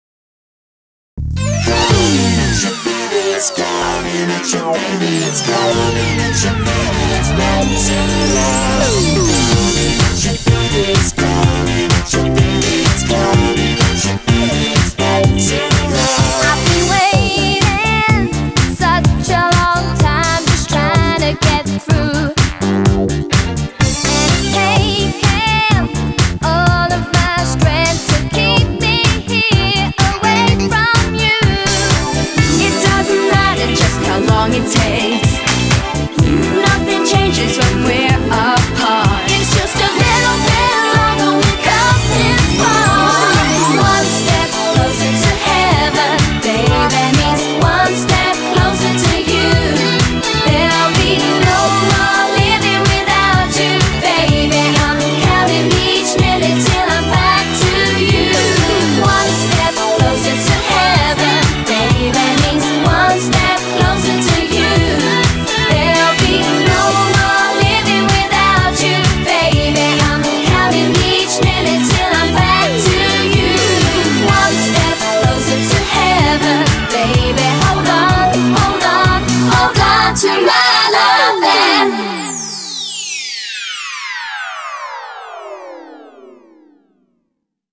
BPM126
Audio QualityPerfect (High Quality)